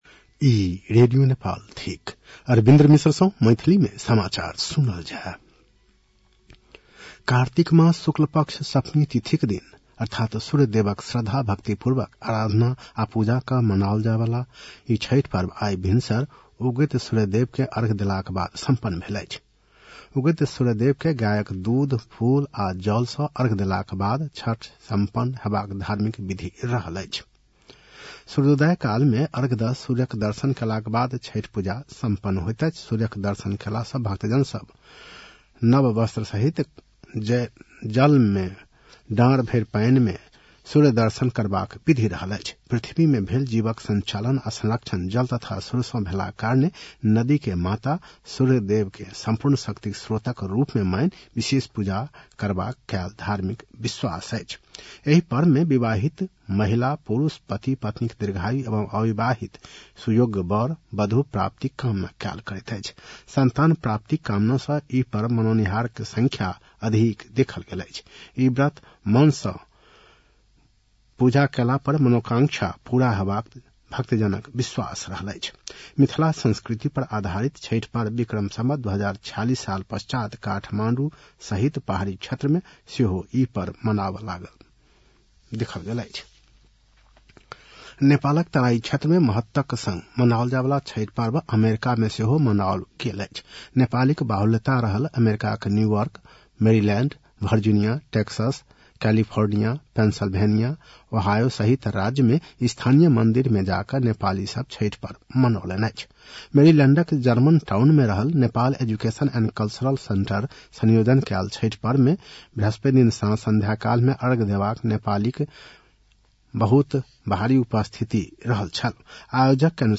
An online outlet of Nepal's national radio broadcaster
मैथिली भाषामा समाचार : २४ कार्तिक , २०८१